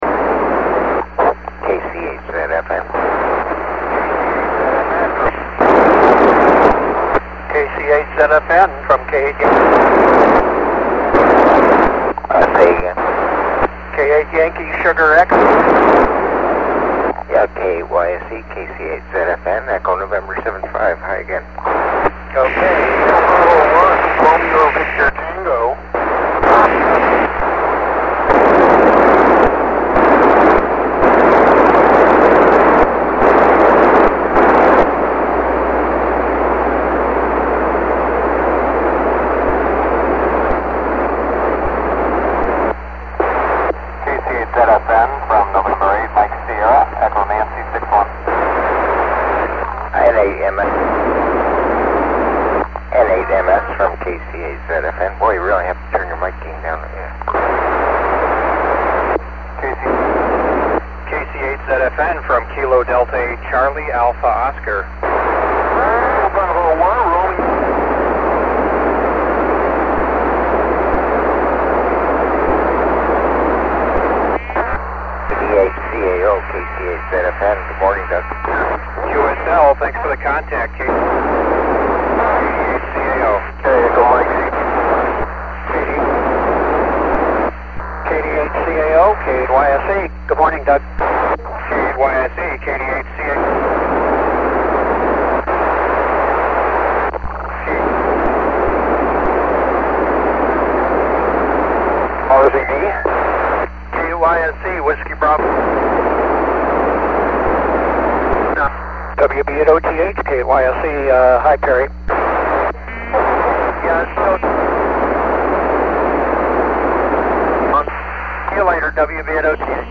The central USA pass was much like the eastern pass. Signals from the satellite were very strong. Audio was very loud, even on the wideband rx setting on the TS2000. Audio cut off after a few seconds of transmission was prevalent. It seems that it took several seconds for the audio to come back when this happened.